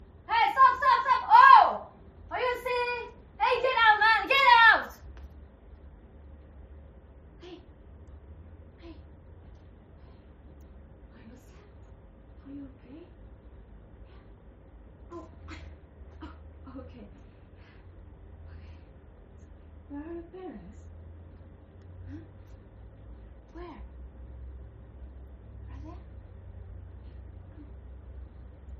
Comédienne
- Mezzo-soprano Soprano